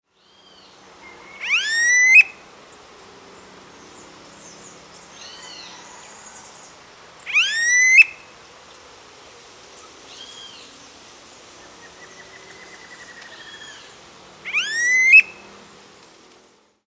Crotophaga ani
São também muito barulhentos.
Aprecie o canto do